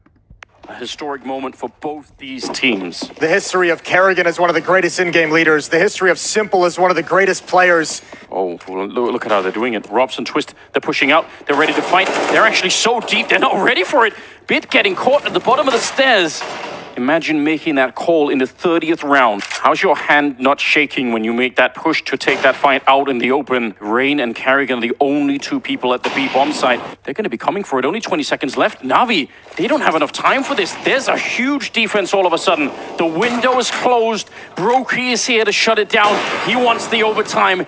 下面是官方放出的电竞比赛解说音频示例。
因此识别结果中，即使电竞解说人员的语速非常快也没有影响识别游戏专业术语的效果。